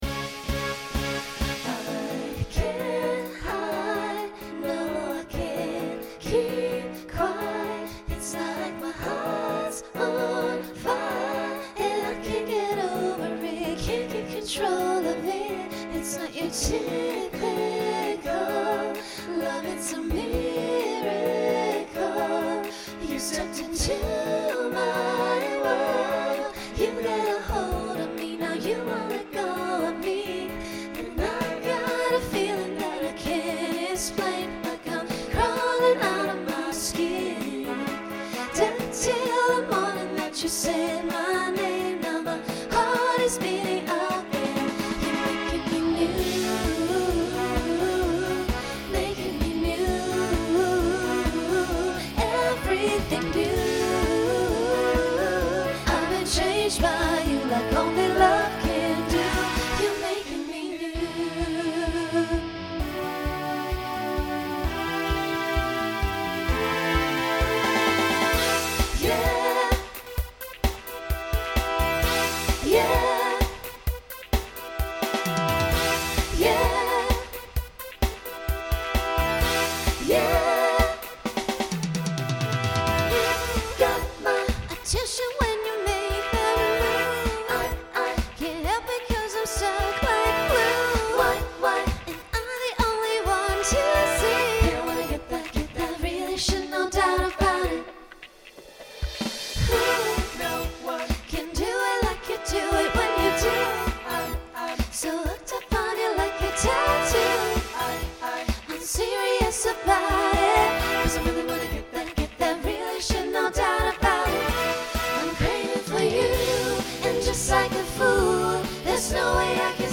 Voicing SATB
Genre Pop/Dance , Rock